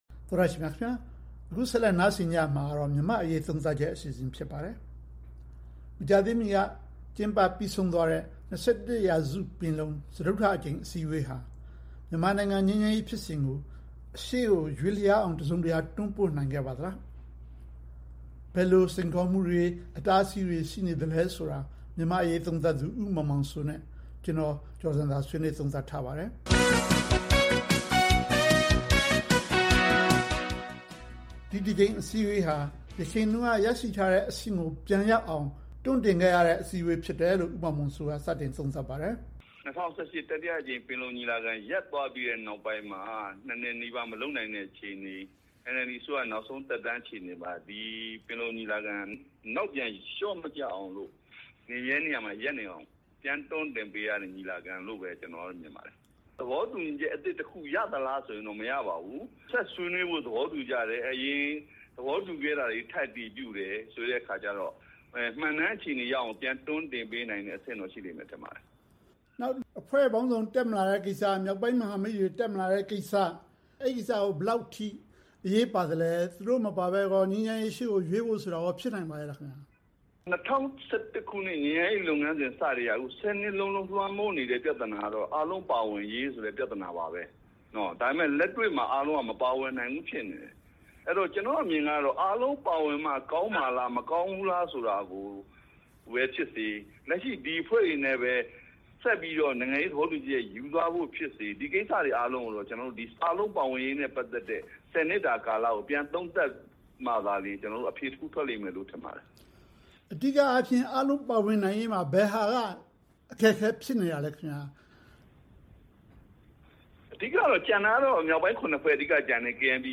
မြန်မာနိုင်ငံ ငြိမ်းချမ်းရေးဖြစ်စဉ် ရှေ့ကိုရွေ့အောင် တစုံတရာ တွန်းပို့နိုင်ခဲ့ပါသလား၊ ဒီလိုရွေ့နိုင်ဖို့အတွက် ဘယ်လို အတားအဆီးတွေ အခက်အခဲတွေ ရှိနေပါသလဲ။ မြန်မာ့အရေးသုံးသပ်သူ